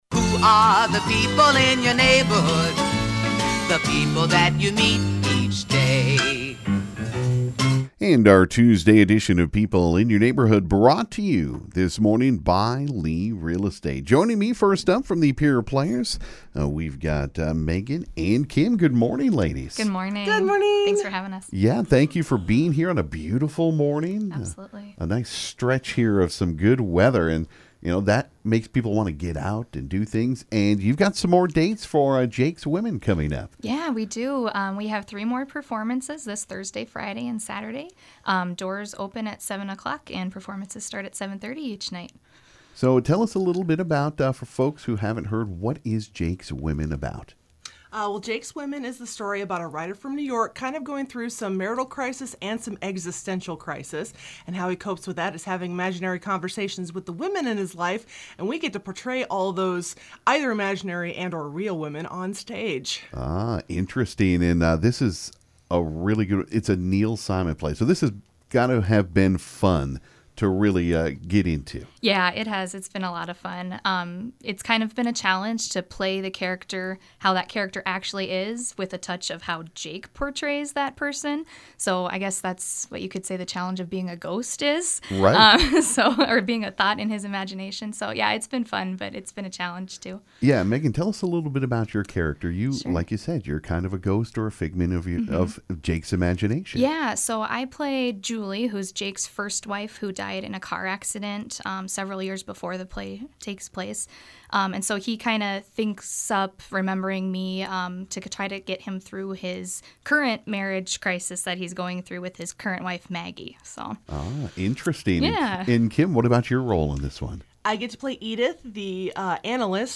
During People In Your Neighborhood this morning on KGFX we had a few guests in studio.